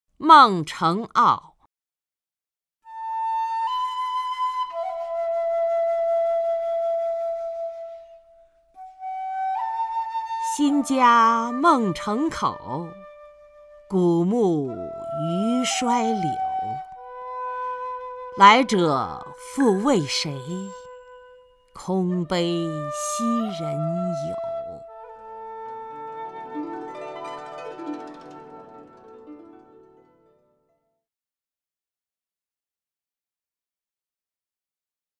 曹雷朗诵：《孟城坳》(（唐）王维) （唐）王维 名家朗诵欣赏曹雷 语文PLUS